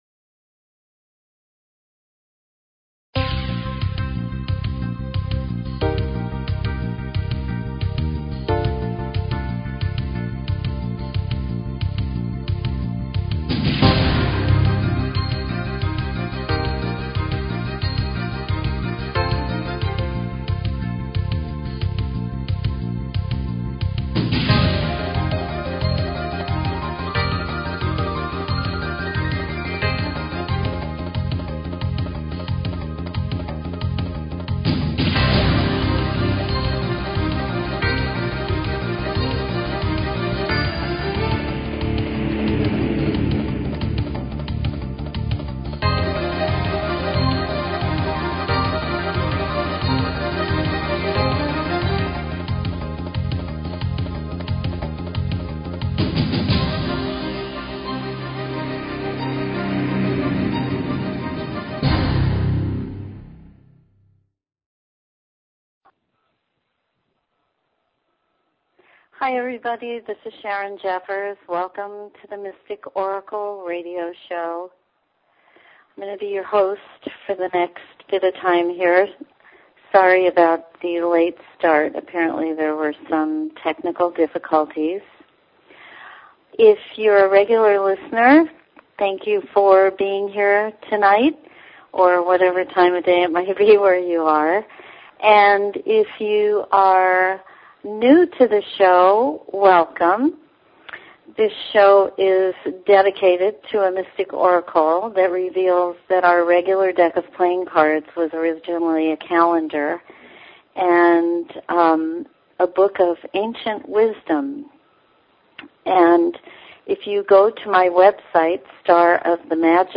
Open lines for calls.